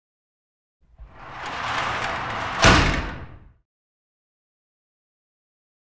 Metal Gate Roll / Slide Close 1.